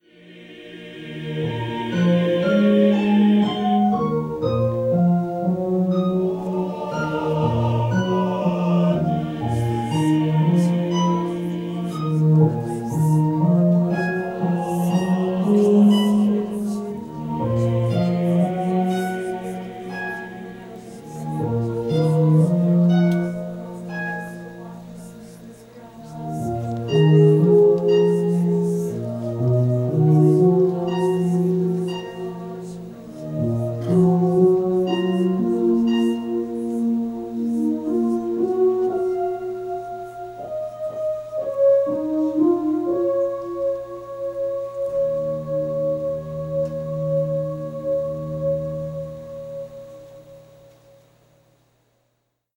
The other program of original music from this time period was a choral concert accompanied by synthesized music. One of our concert venues was the Russell Planetarium in Jackson, MS. The texts were biblical and the confluence of performance in a planetarium (with slides on loan from NASA), the use of synthesizers, and the setting of imagery that often invoked celestial objects, was deliberate. In this one excerpt, from “Lift Up Your Eyes,” there is a place where the womens’ voices repeat a section of text ad libitum under the sound of a men’s chorus and synthesizer solo.